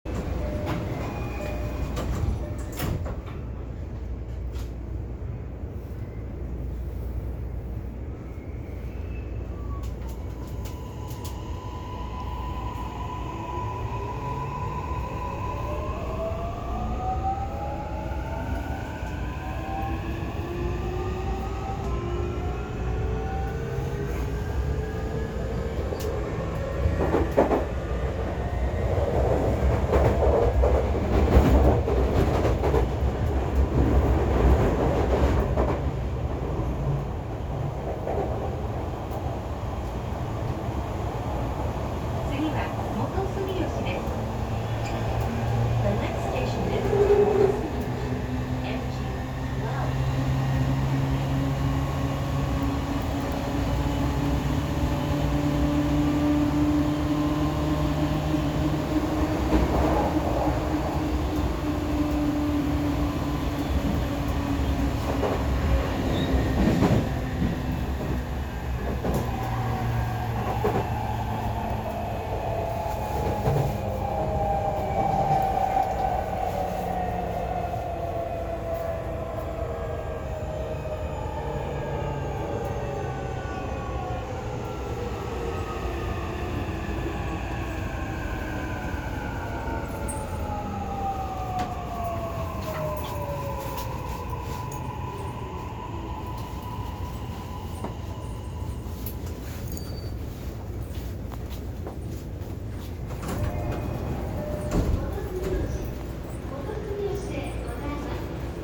・6500形走行音
【東急目黒線】武蔵小杉→元住吉
都営地下鉄は三菱のモーターばかり使っているイメージがありますが、6500形とてその例外ではなく三菱SiCとなりました。とはいえ、5500形のようなとんでもない音ではなく、音量もごく普通です。
kosugi-motosumi.mp3